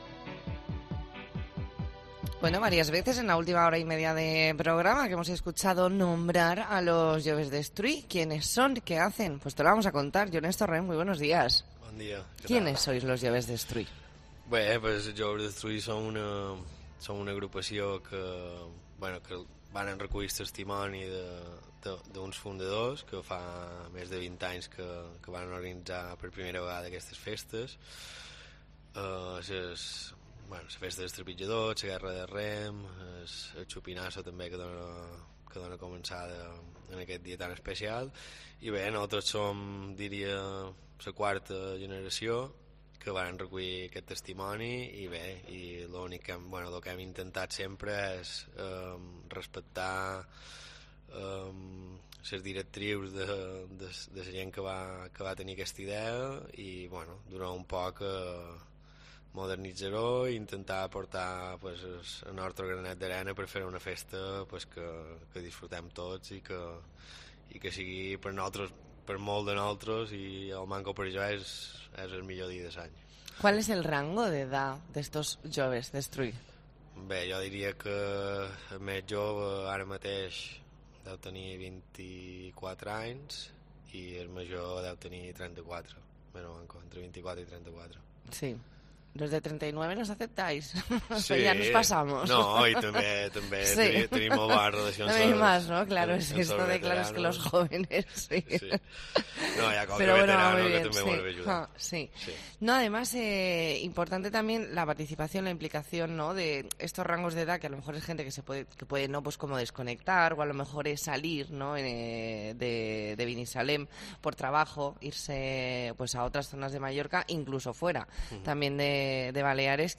Entrevista en La Mañana en COPE Más Mallorca, jueves 22 de septiembre de 2022.